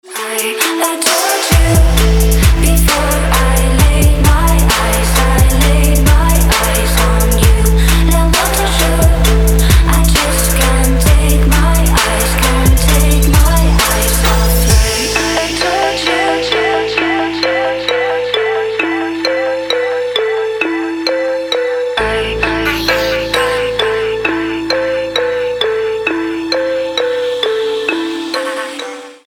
• Качество: 256, Stereo
женский вокал
Trap
трэп